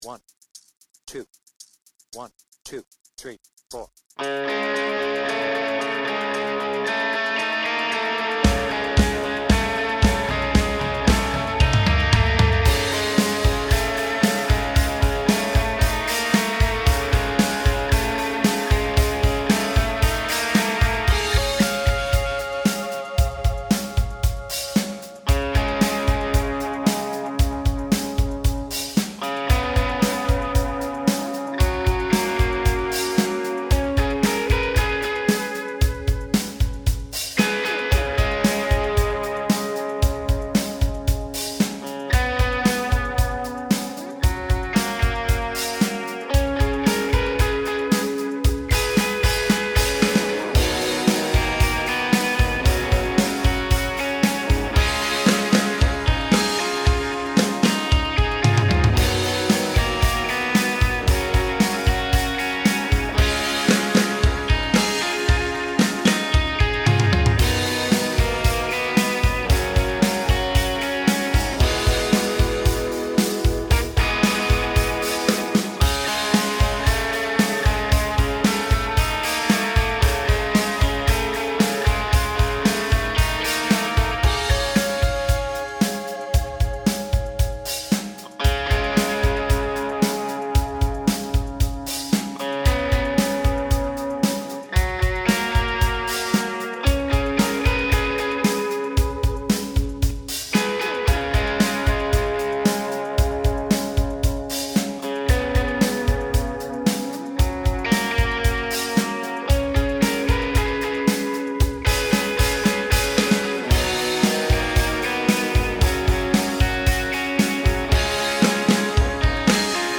Without vocals
Based on the Vertigo Tour